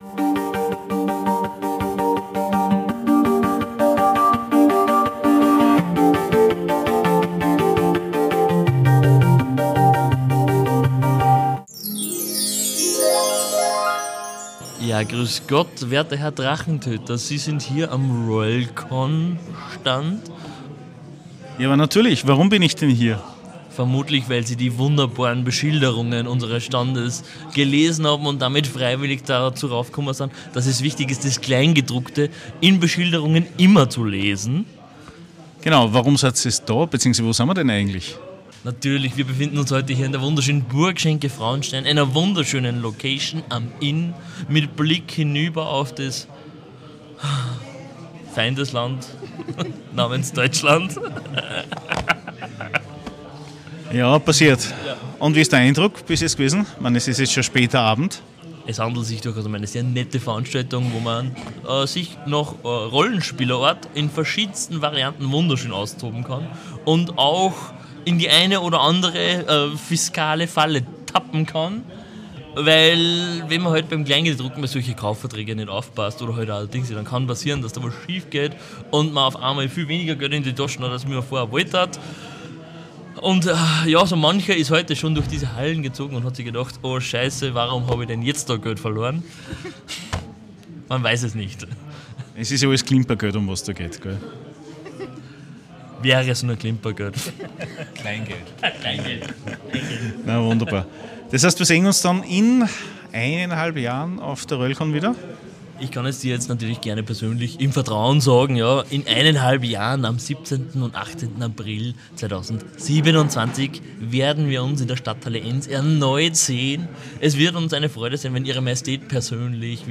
Episode 206 - Game Inn Braunau - Convention - Die Interviews ~ Der Drachentöter Podcast